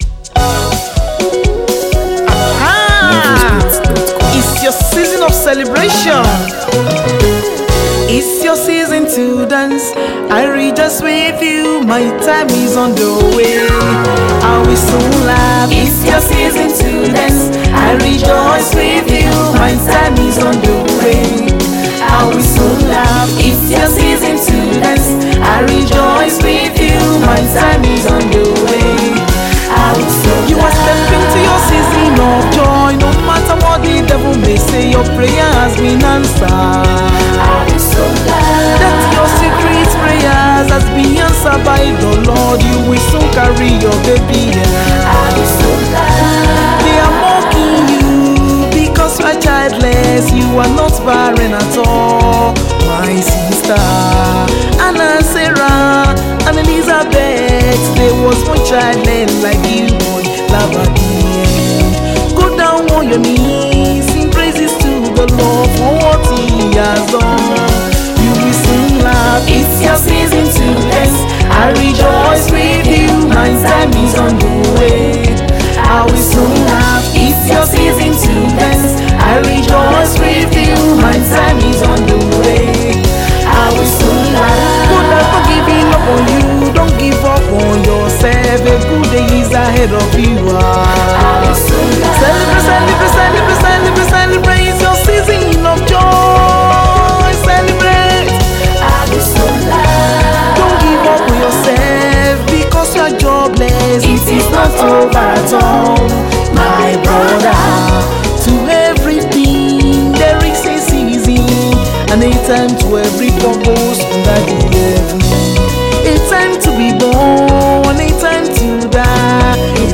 Talented Nigerian gospel singer and songwriter
infectious beat